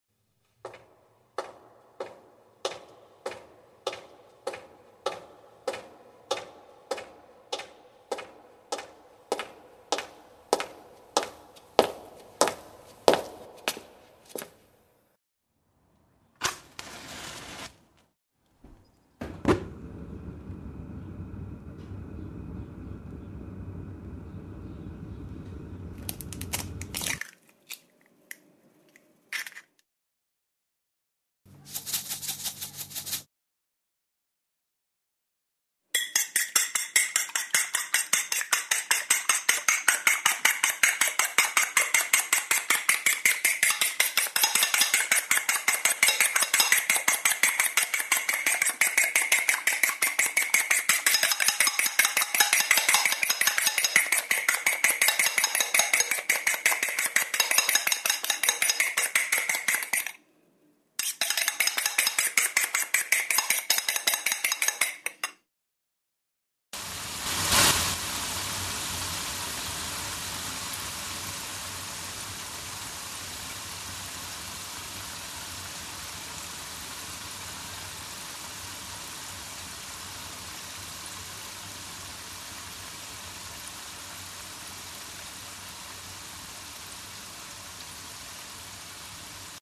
Expliquem una història amb efectes sonors i música
Us proposem de construir una història només amb efectes sonors. La situació que es proposa és molt casolana: anem a fer una truita!
Persona caminant
Parant la taula
Batent els ous
Encenent el foc de gas
Tirant els ous a la paella
Encenent un misto
Posant la sal
Trencant un ou